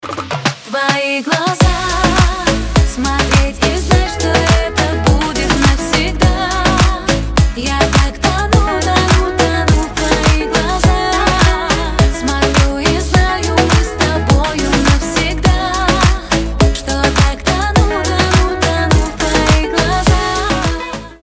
• Качество: 320, Stereo
поп
женский вокал